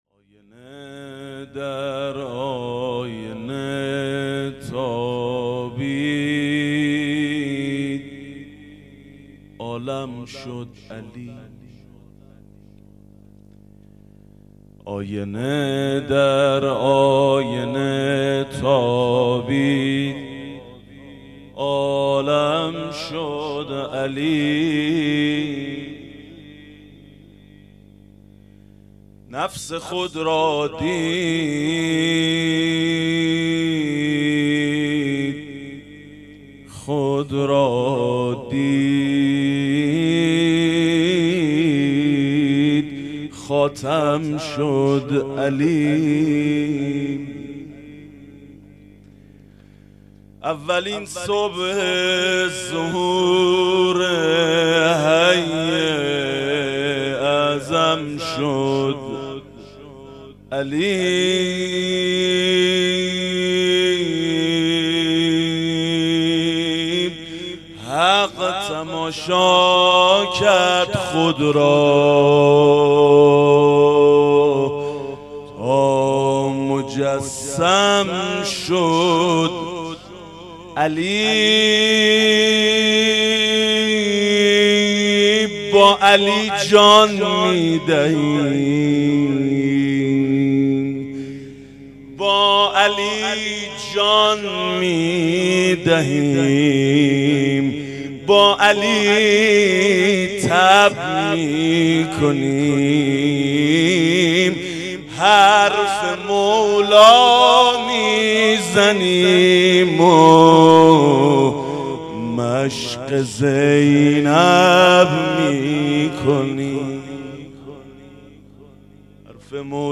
روضه شب دوم محرم 97